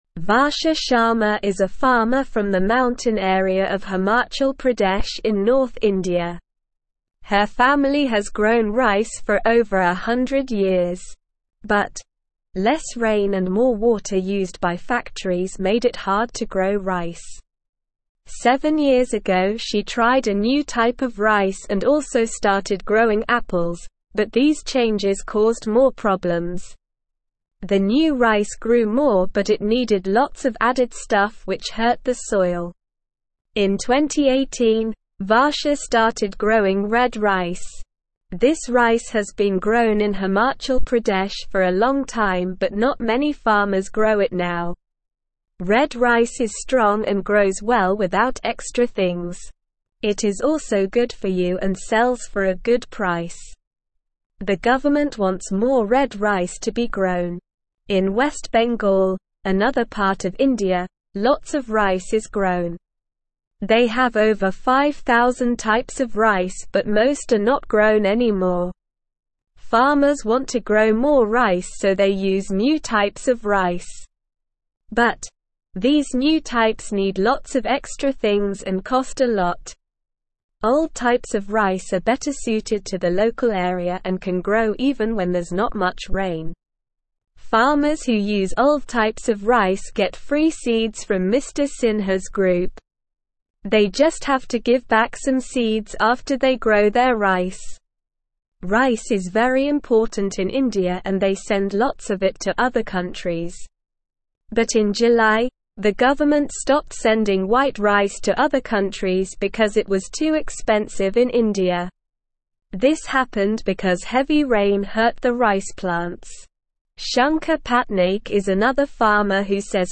Slow
English-Newsroom-Beginner-SLOW-Reading-Indian-Farmers-Try-New-Ways-for-Rice.mp3